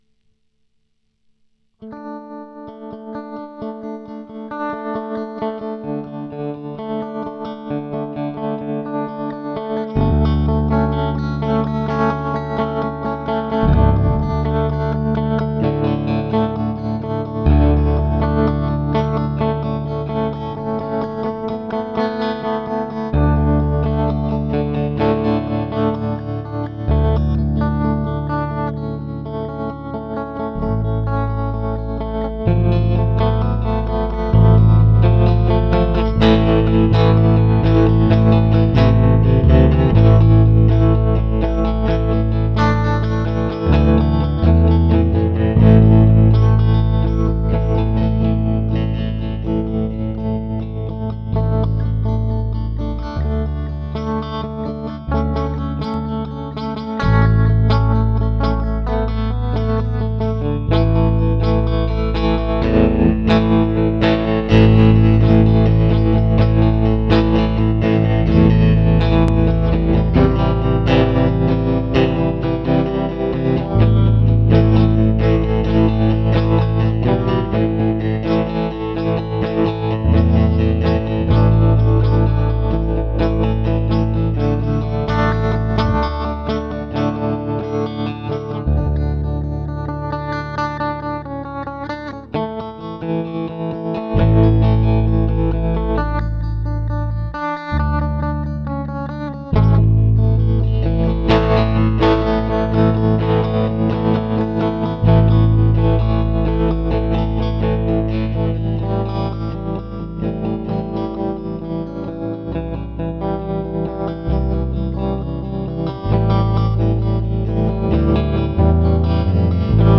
С басом налажал, ибо не играю на нём